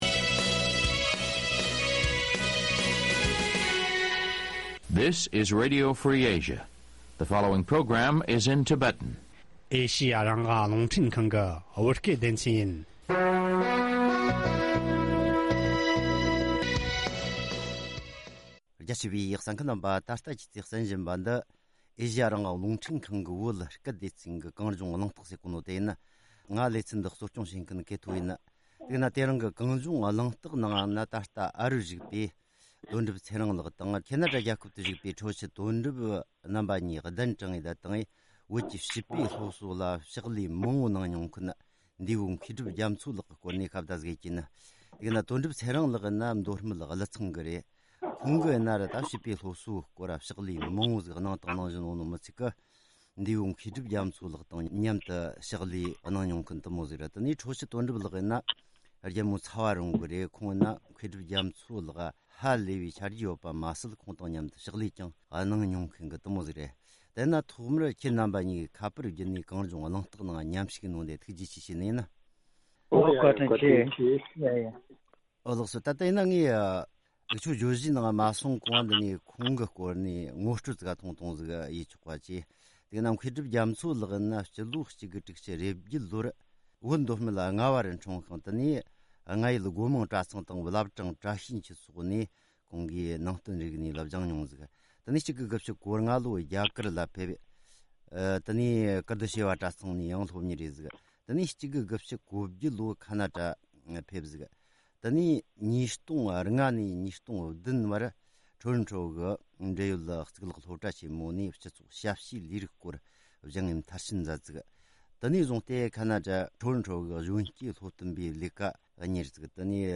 བཅར་འདྲི་ཞུས་པའི་ལས་རིམ།